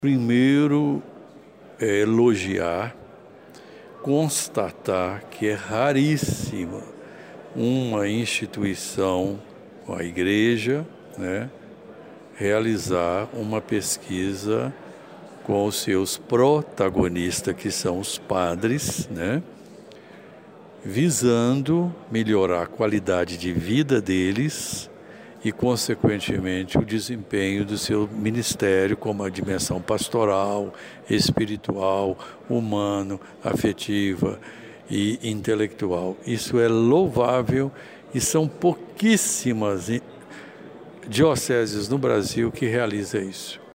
O psicólogo destacou durante a entrevista os efeitos e reflexos dos escândalos atuais na vida dos padres: